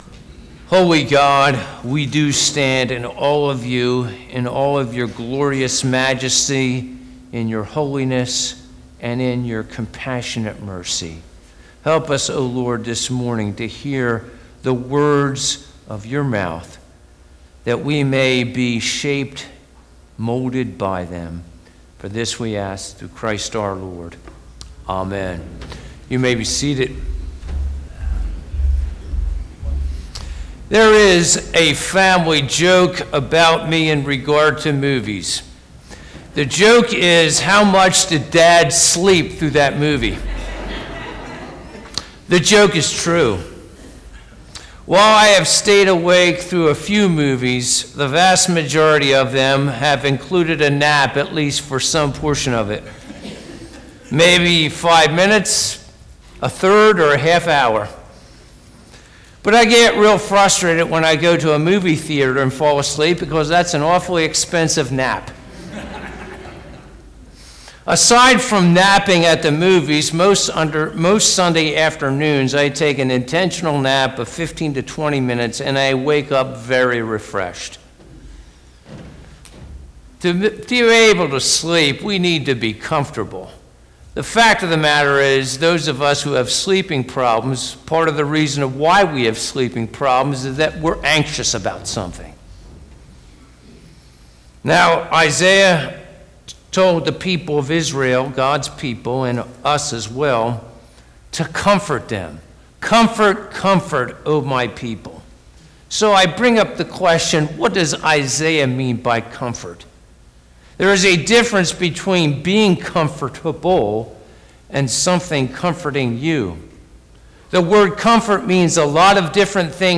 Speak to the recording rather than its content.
Isaiah 40 Service Type: Sunday Morning « A Song of Praise to God What Were the Reactions to the Lord Jesus on Palm Sunday?